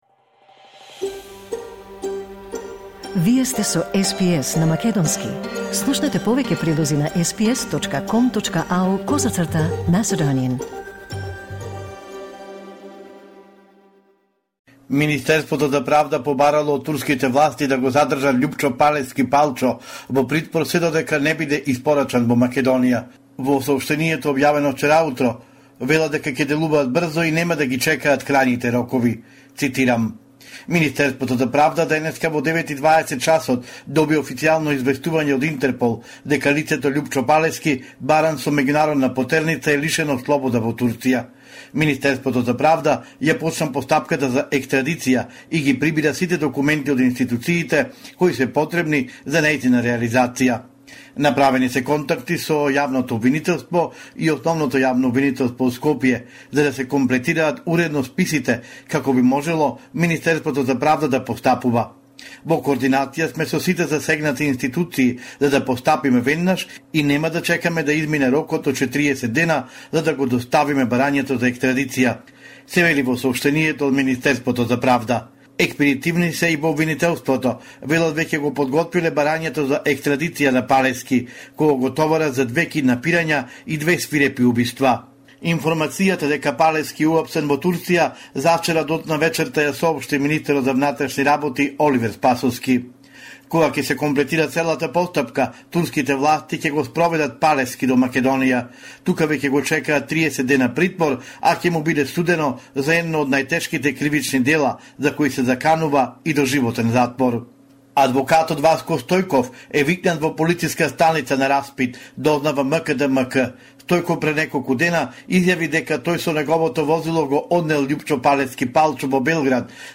Homeland report in Мacedonian 7 December 2023